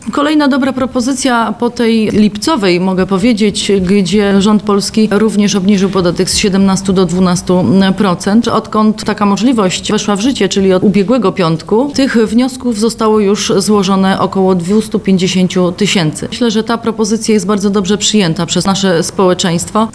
Polega ona na finansowym odciążeniu w znacznym stopniu gospodarstw rodzinnych, mówi poseł PiS Agnieszka Górska: